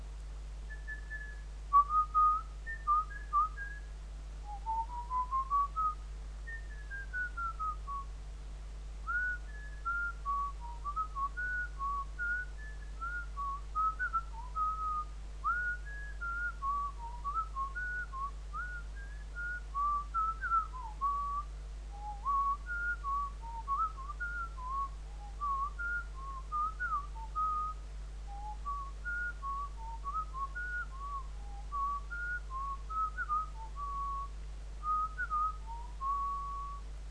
607-gwizd01-wav